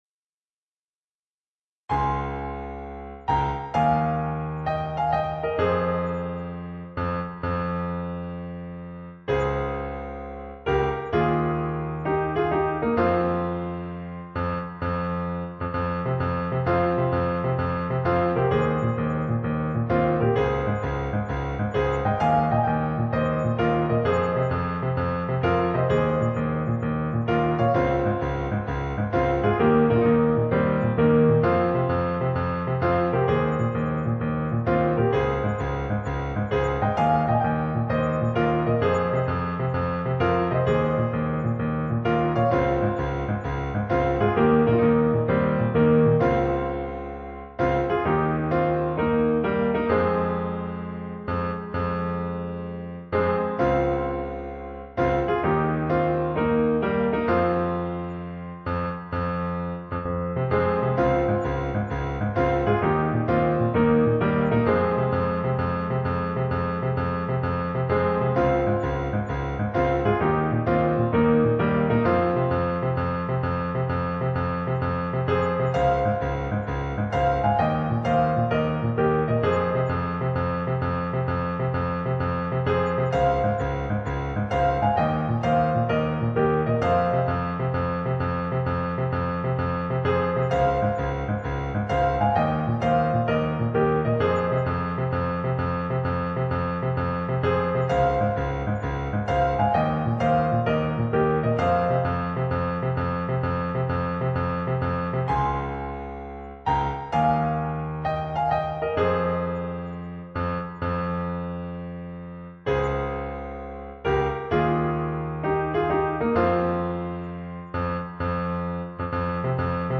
ピアノ用譜面